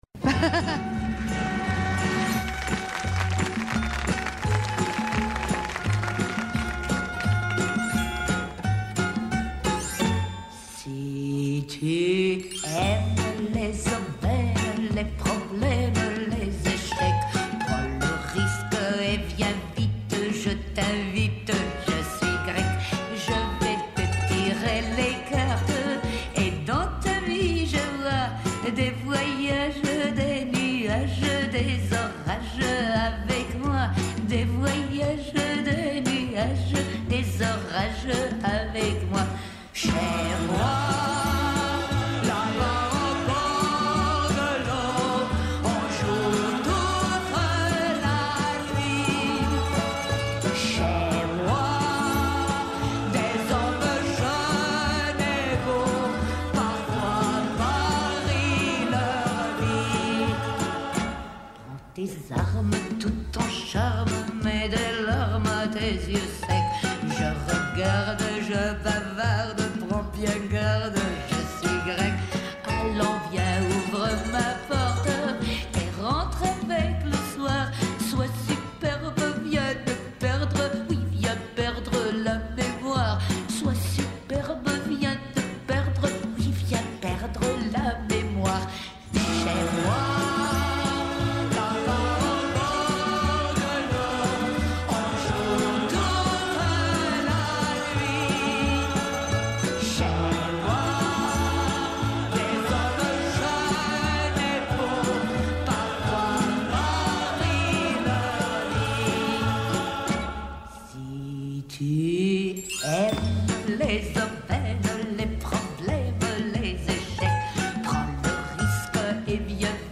Στην καθημερινότητά μας. 9 με 10, κάθε πρωί Δευτέρα με Παρασκευή. ΠΡΩΤΟ ΠΡΟΓΡΑΜΜΑ